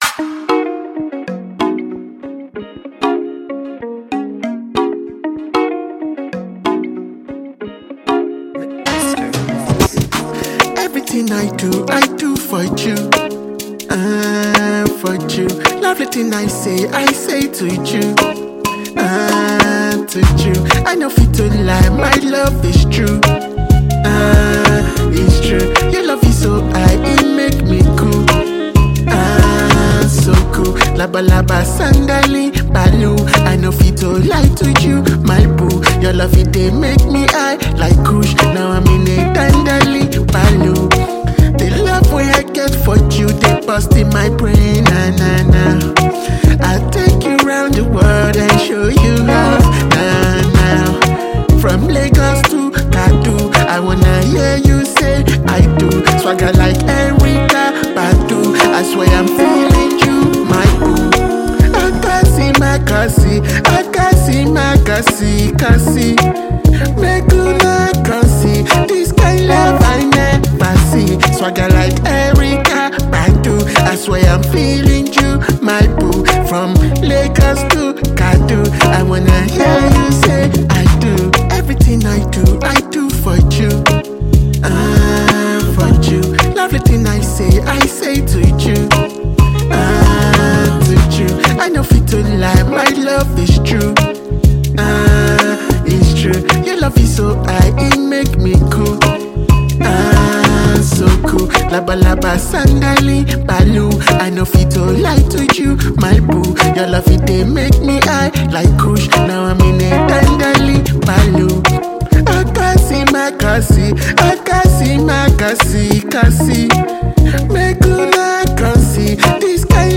love anthem